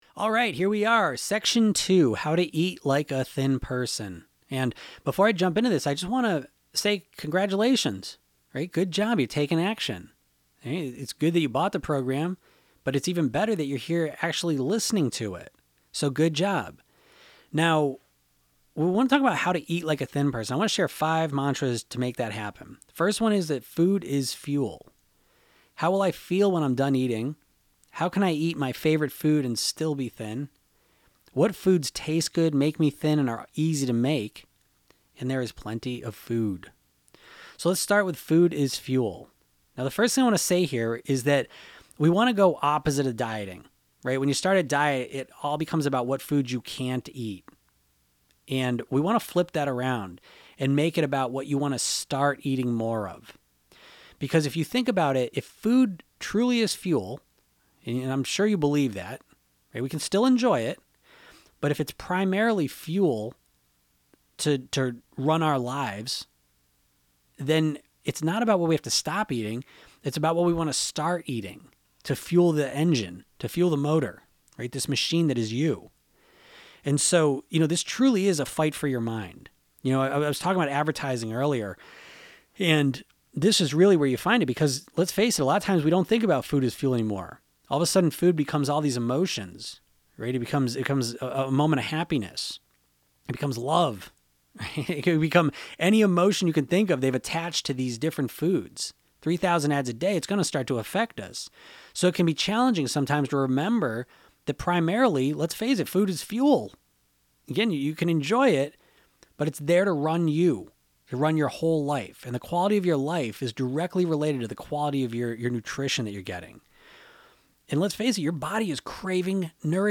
Self Hypnosis Session:How To Eat Like A Thin Person